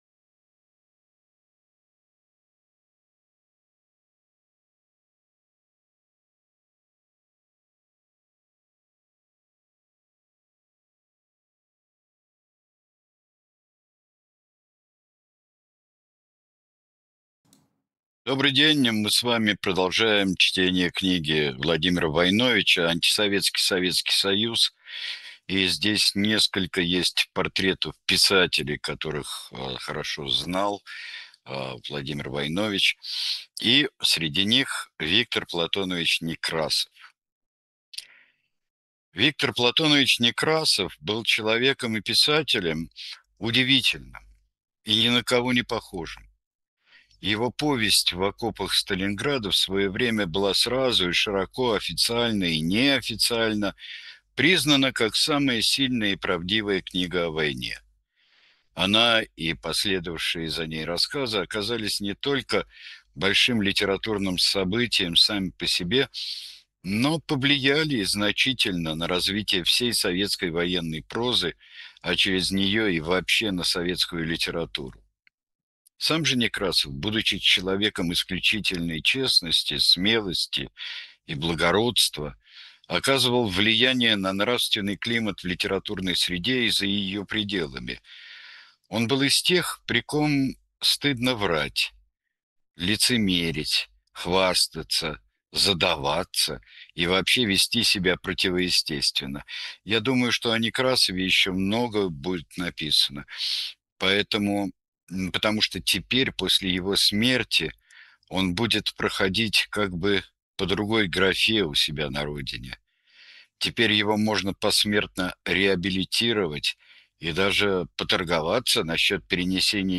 Читает Сергей Бунтман